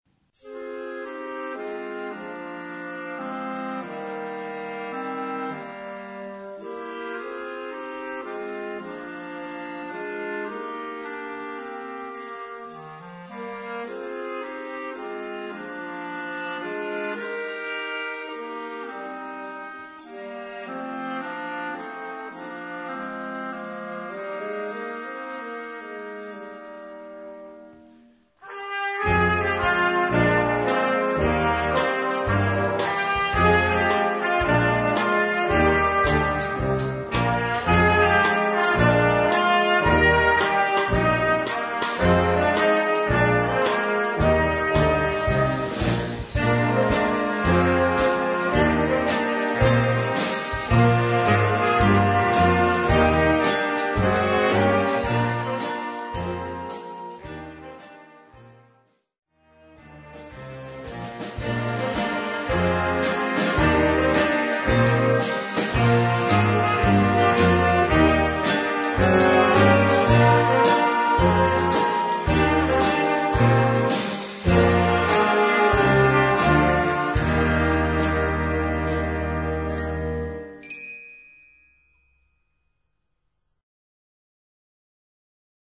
A5-Quer Besetzung: Blasorchester PDF: Tonprobe